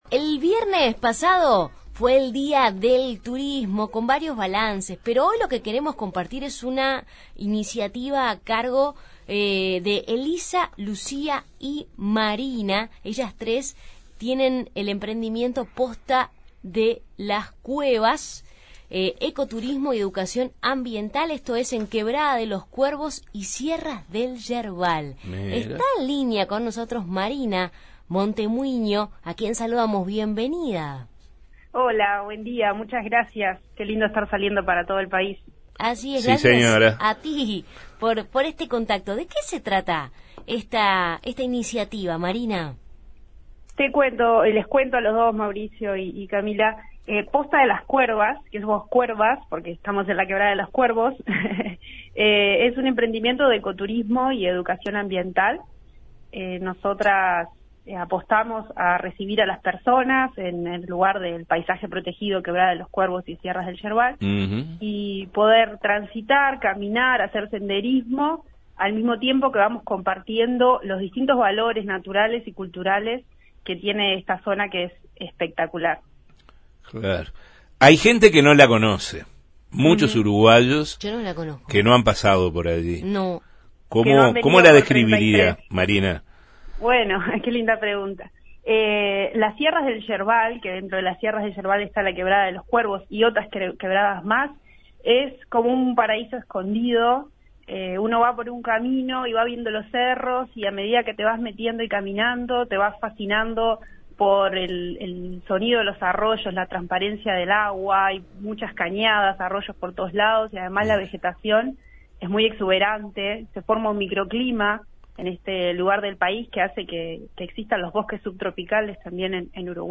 En diálogo con Justos y pecadores